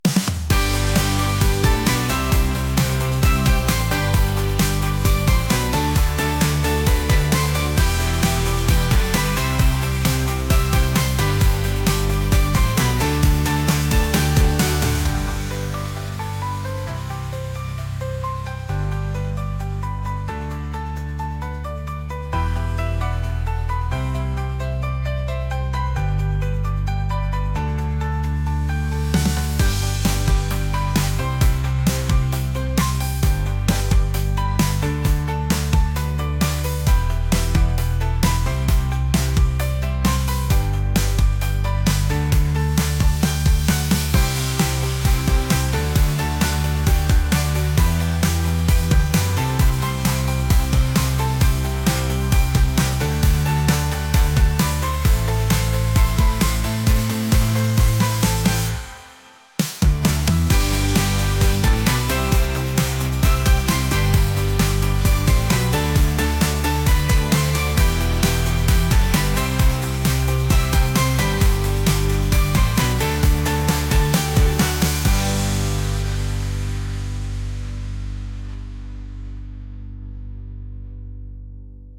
energetic | upbeat | pop